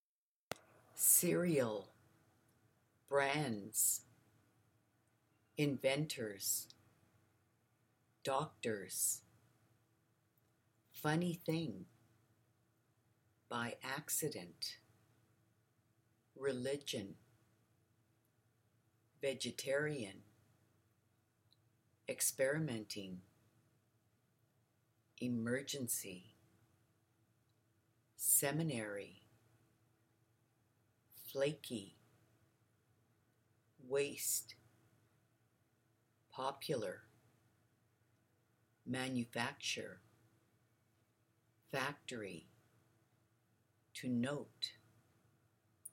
The-story-of-corn-flakes-Vocabulary.mp3